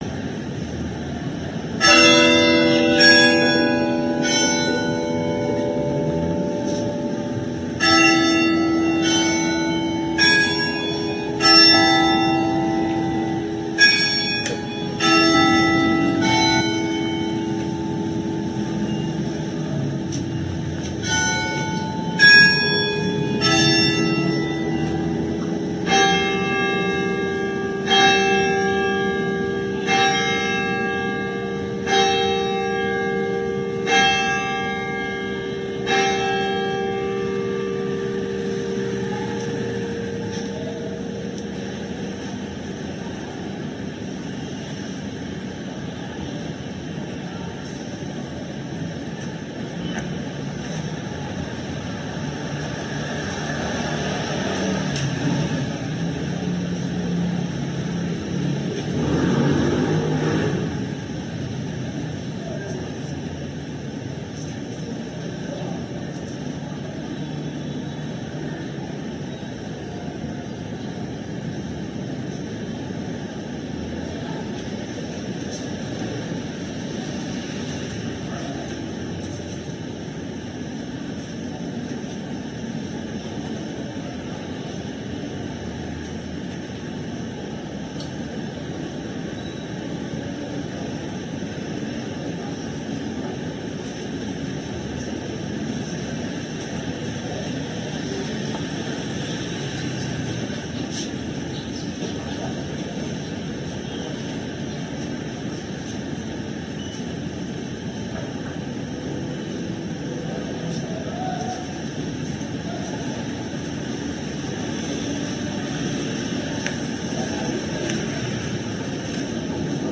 Thirteen Minutes of tolling of bells in remembrance of Pope Francis’ thirteen years of Petrine Ministry.
CHURCH-BELLS-FULL-COMP.mp3